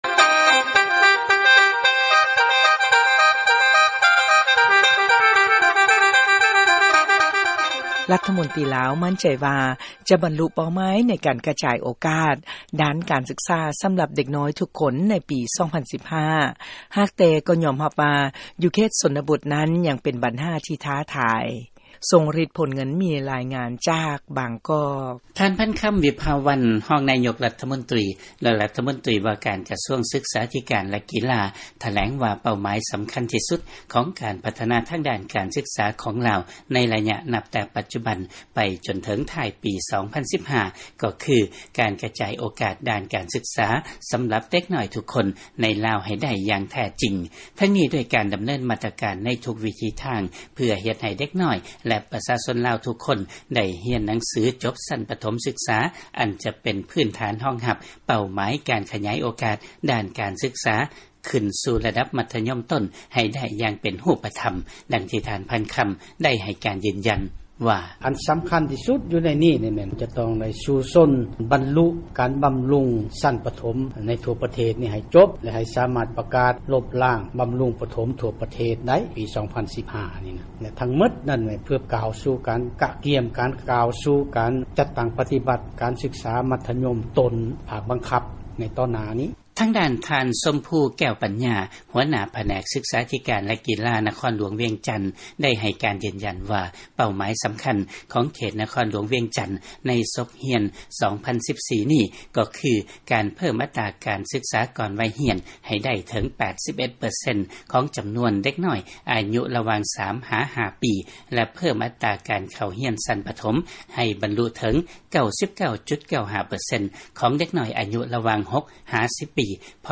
ຟັງລາຍງານ ລມຕ ລາວ ໝັ້ນໃຈວ່າ ຈະບັນລຸເປົ້າໝາຍ ໃນການກະຈາຍໂອກາດ ດ້ານການສຶກສາ ໃຫ້ເດັກນ້ອຍທຸກຄົນໃນປີ 2015.